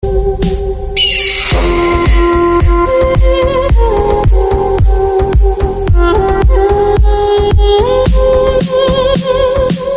Eagle_Short_v1.mp3